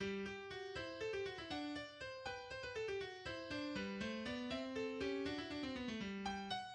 G-Dur, 3/8-Takt, 323 Takte
Das erste Thema wird zunächst von den beiden Violinen vorgestellt (stimmführend 1. Violine, Gegenstimme 2. Violine), der Kopf des Themas – ein vom Eingangsthema des Allegros abgeleitetes Viertonmotiv[6] – läuft dann versetzt durch die Instrumente und wird ab Takt 17 zweimal forte-unisono im inzwischen erreichten A-Dur wiederholt.